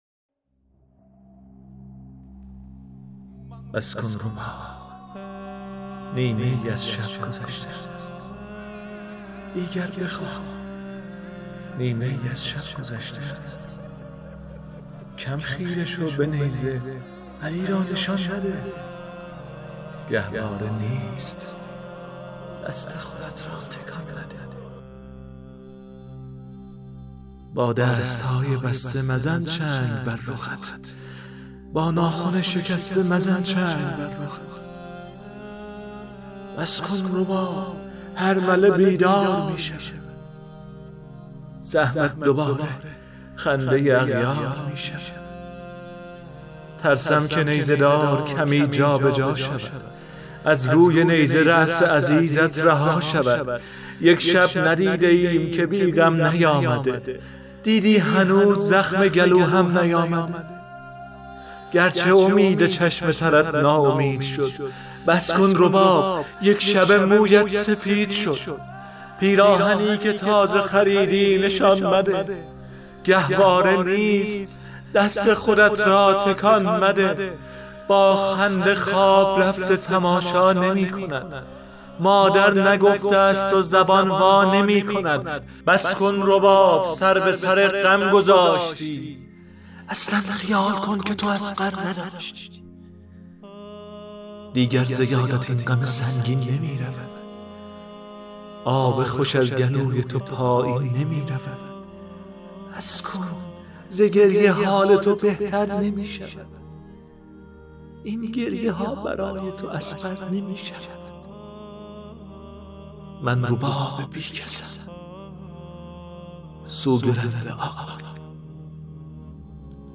شعر خوانی فارسی و ترکی؛ زبان حال حضرت رباب در فراغ حضرت علی اصغر علیه السلام